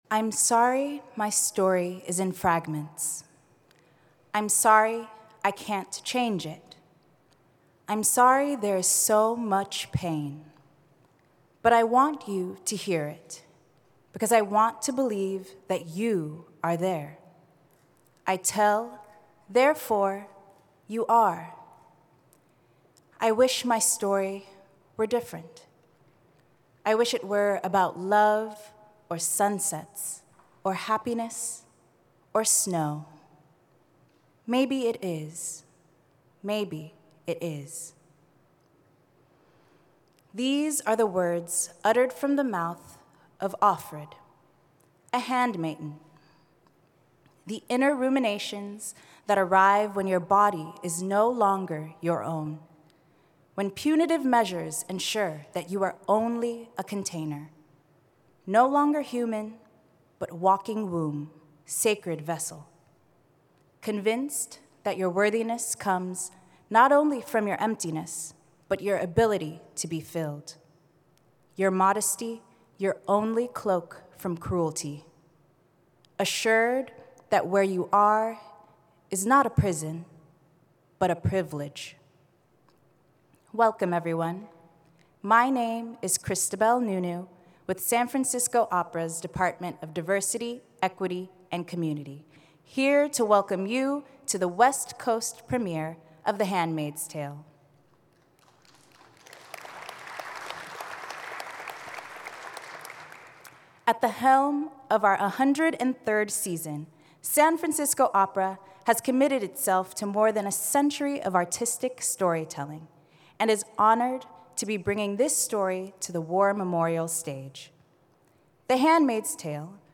handmaids_pre-show_lecture.mp3